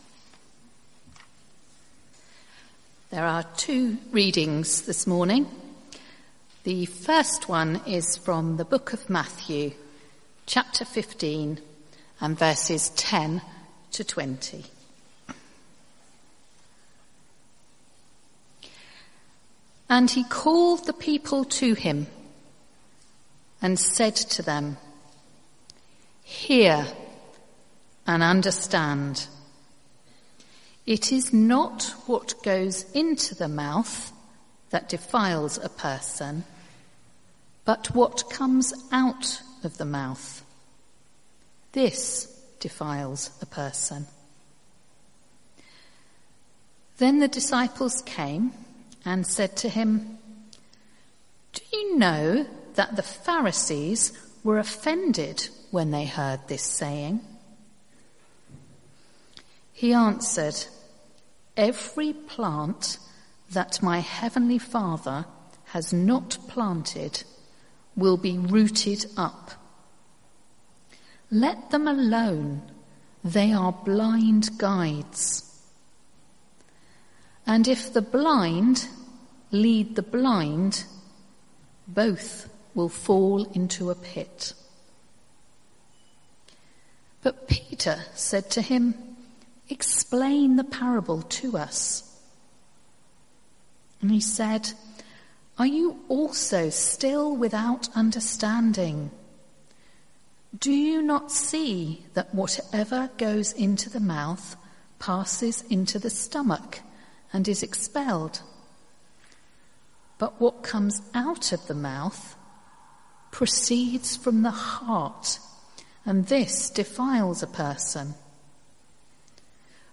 Sermon Series: Taming the Tongue | Sermon Title: The Power of the Tongue